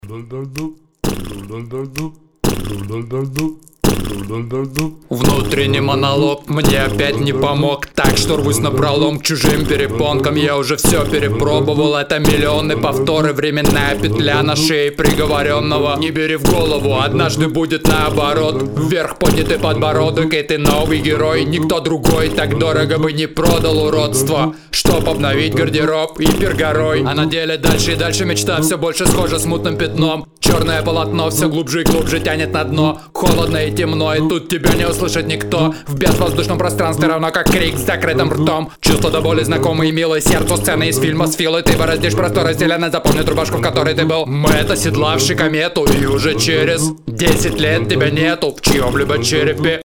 Подобный стафф я бы не слушал, читка монотонная, битбокс только раздражает и отвлекает от слов.
Оригинально, текст неплохой, но связь с темой натянута, ну и слушать скучно - хоть ты битбокс разнообразил